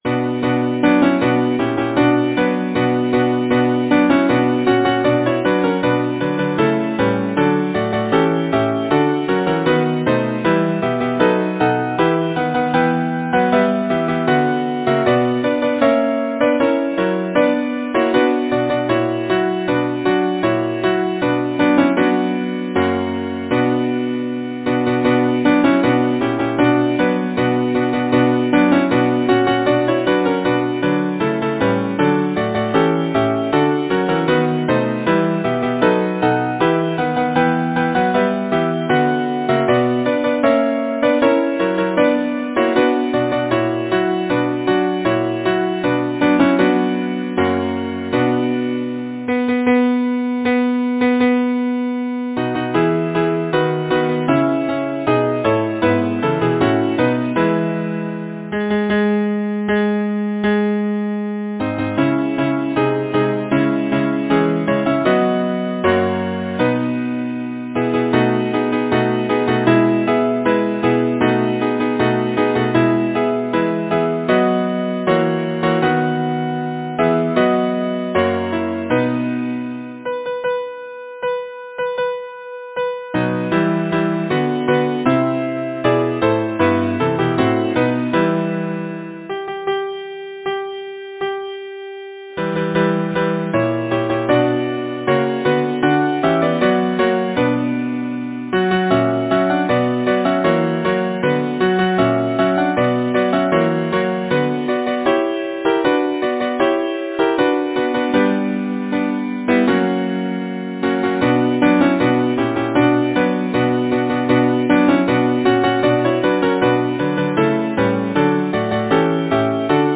Title: The voice of Spring Composer: John George Veaco Lyricist: Felicia Hemans Number of voices: 4vv Voicing: SATB Genre: Secular, Partsong
Language: English Instruments: A cappella